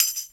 D2 SHAK-72.wav